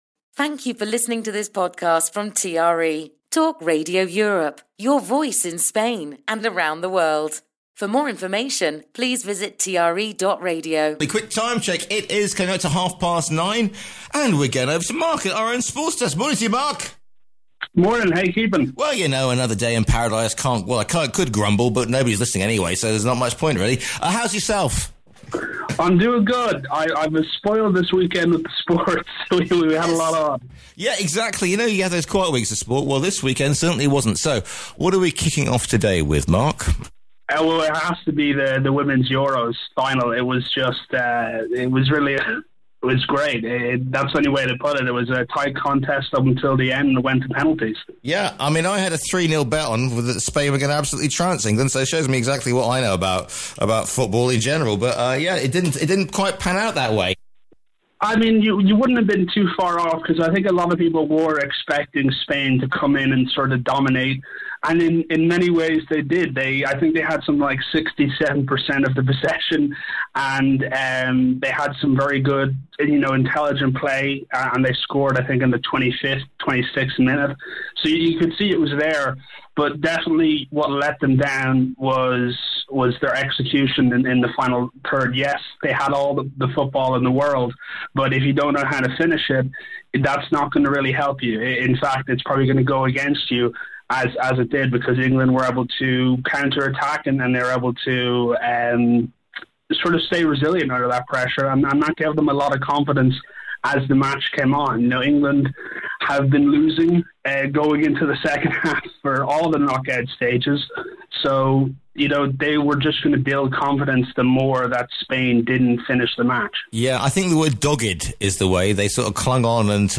as a segment in TRE's flagship breakfast show